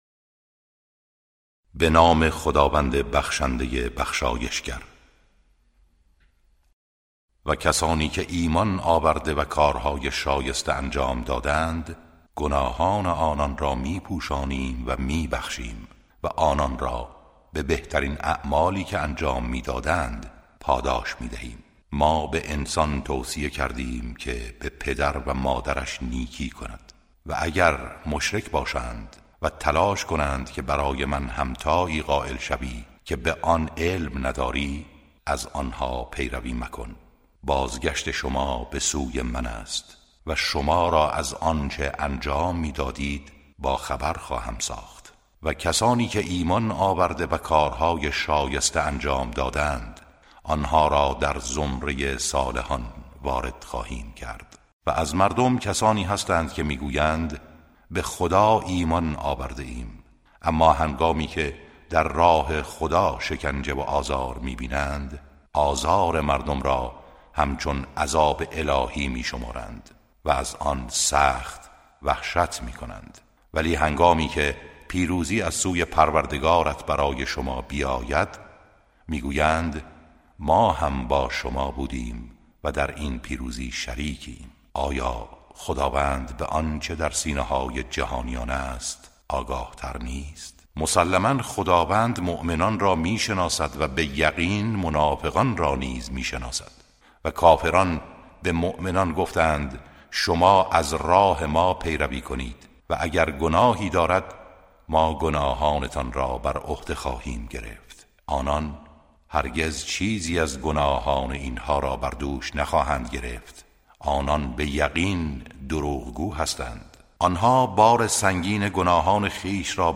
ترتیل صفحه ۳۹۷ از سوره عنکبوت(جزء بیستم)